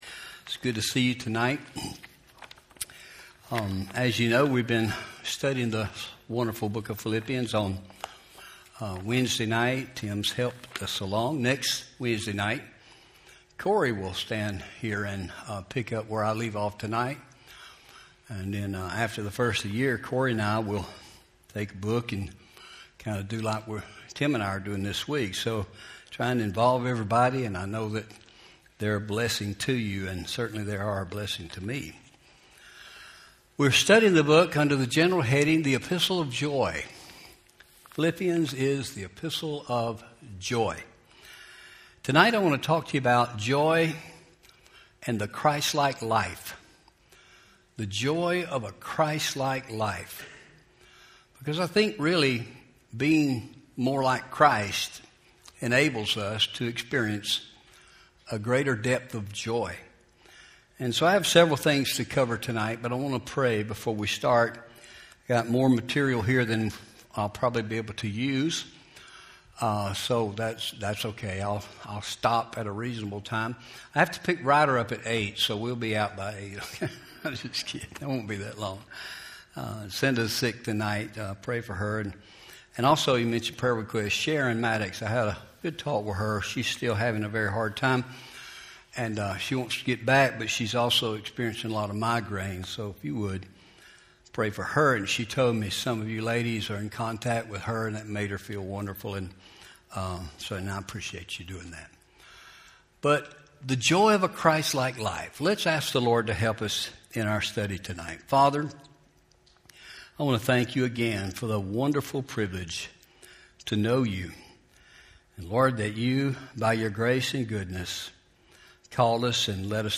Home › Sermons › The Joy Of A Christlike Life